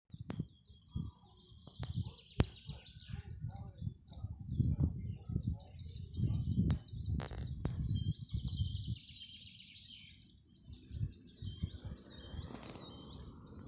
пересмешка, Hippolais icterina
Ziņotāja saglabāts vietas nosaukumsGulbenes nov.Vecstāmeriena
СтатусПоёт